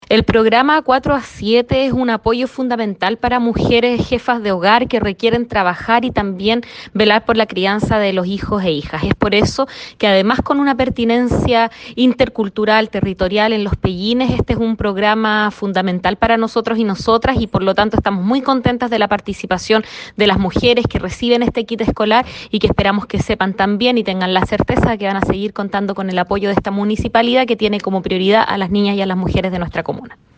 cuña-ALCALDESA-DE-VALDIVIA-CARLA-AMTMANN-FECCI.mp3